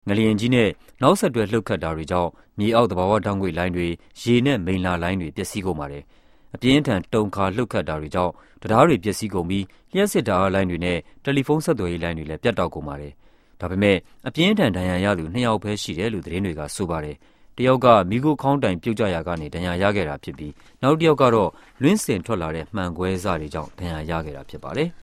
5 September 2010 at 1:21 pm It sounds like a tonal language with a simple syllabic structure.